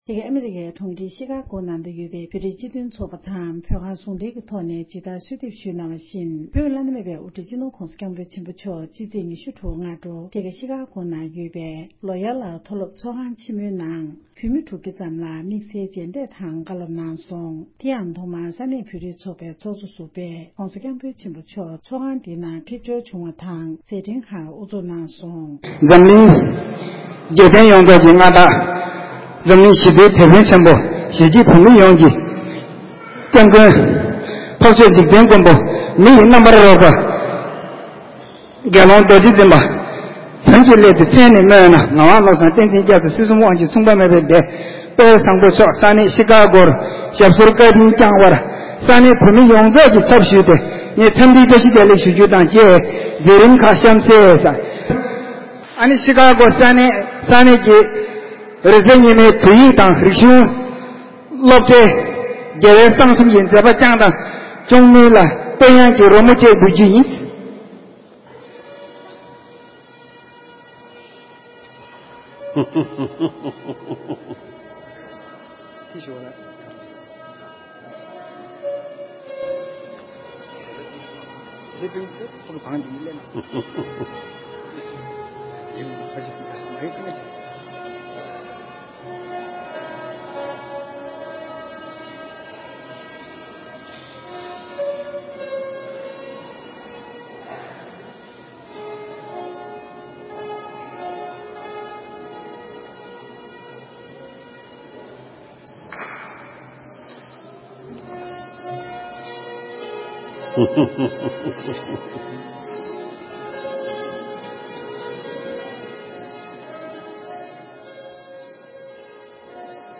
ཕྱི་ཚེས་ ༢༦ ཉིན་༸གོང་ས་མཆོག་ནས་ཨ་རིའི་གྲོང་ཁྱེར་ཆི་ཁ་ཀོ་ས་གནས་བོད་མི་༦༠༠ལྷག་བཀའ་སློབ་བསྩལ་གནང་མཛད་ཡོད་པ།